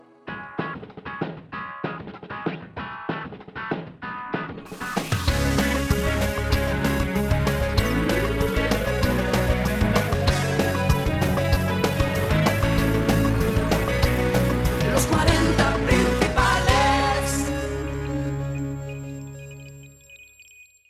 Indicatiu llarg